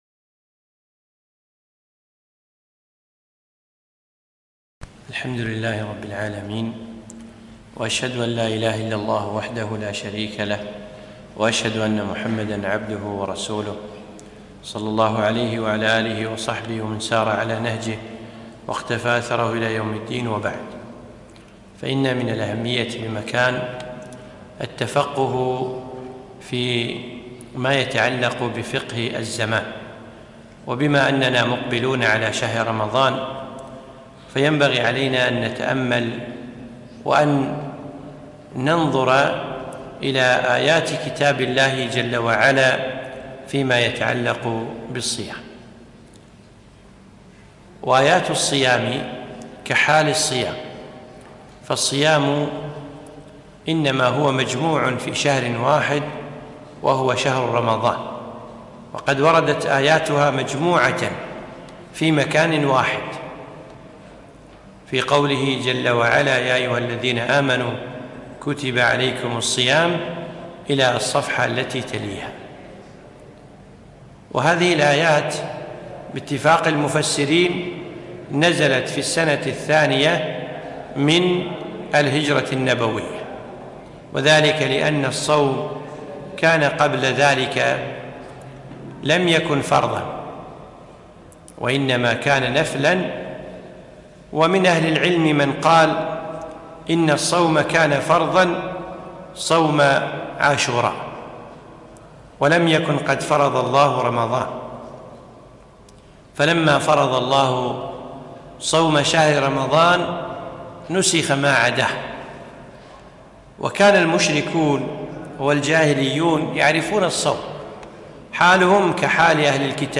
محاضرة - تأملات في آيات الصيام